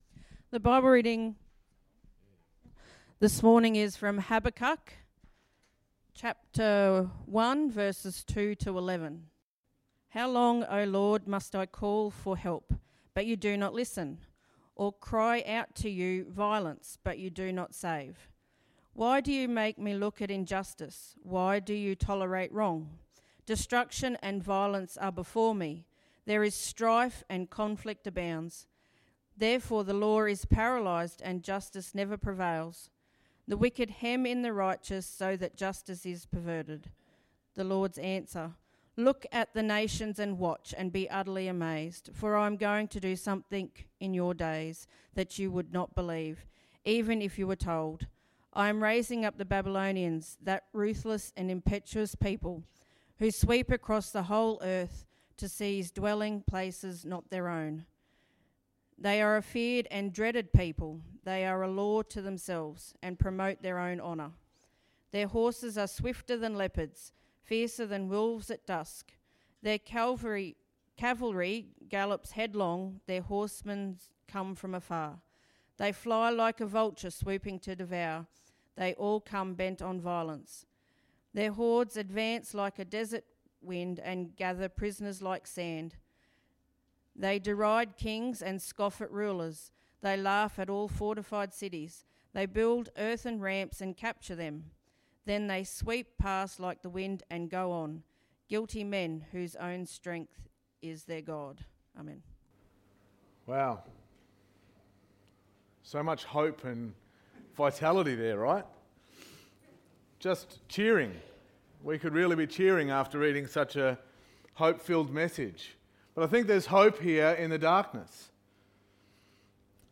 Sermon 11.08.19